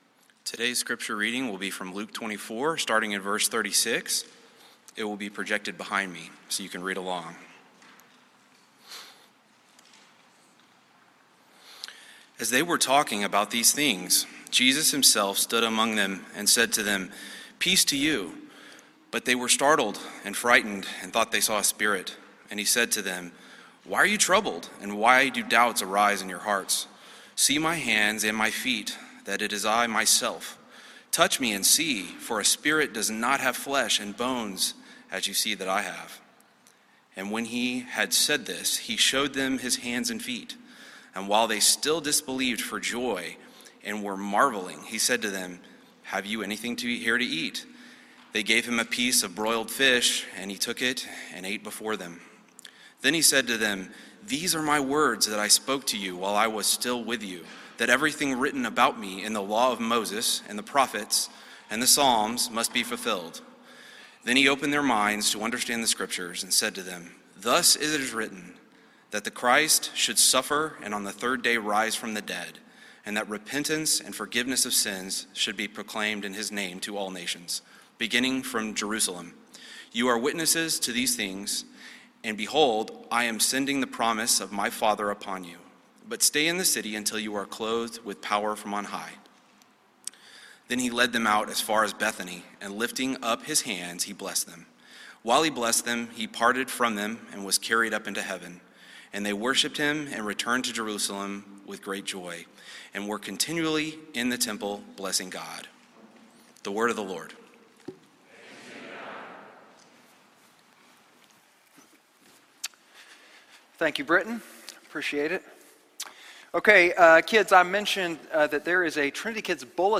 Sermons Home Sermons